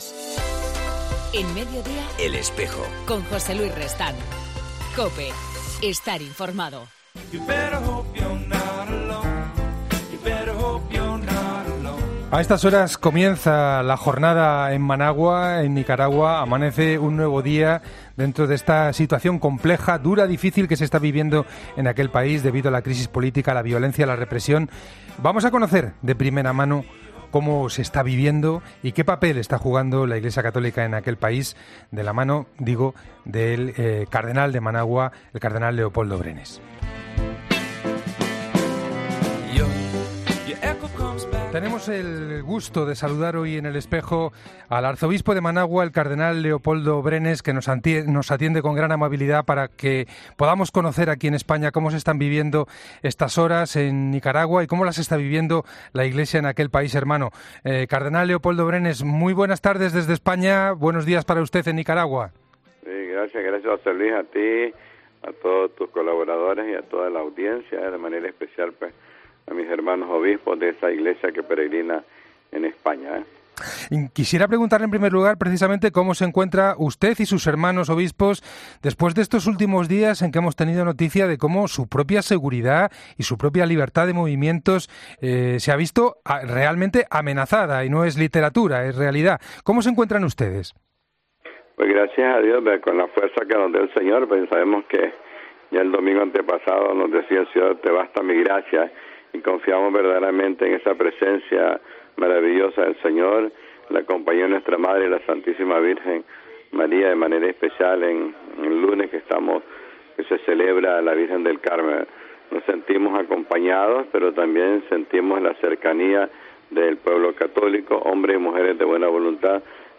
El cardenal Leopoldo Brenes ha valorado este martes en 'El Espejo' de COPE el conflicto que atraviesa su país y en especial la situación en la que se encuentran los obispos nicaragüenses , que están en el punto de mira del presidente Daniel Ortega y de sus seguidores a pesar del intento de ejercer de mediadores entre los manifestantes y el Gobierno.